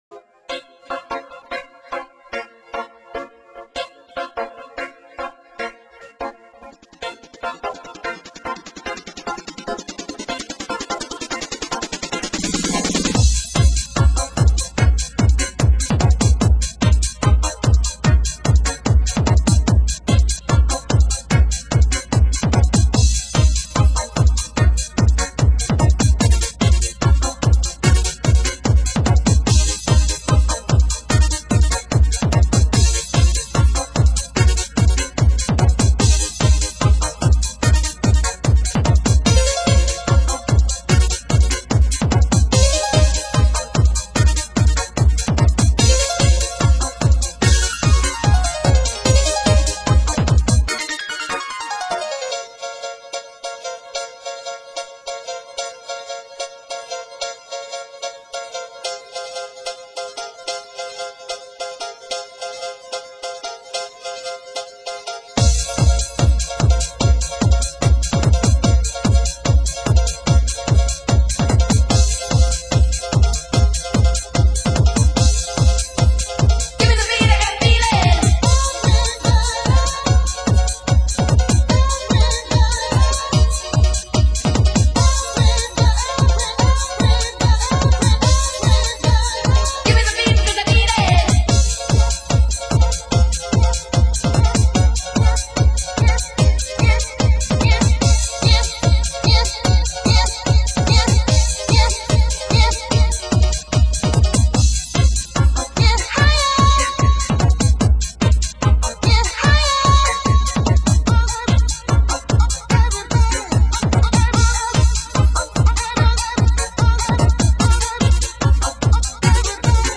My first attempt at a more trancy feel to my music.